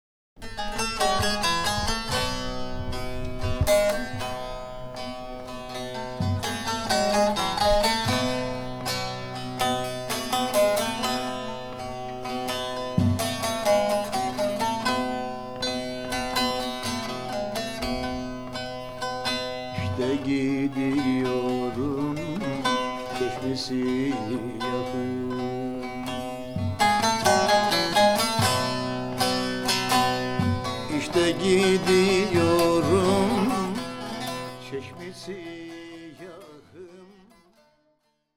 Genres: Turkish Traditional, Folk.